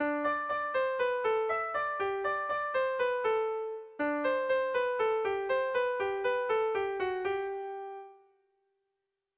Erlijiozkoa
Lauko txikia (hg) / Bi puntuko txikia (ip)
AB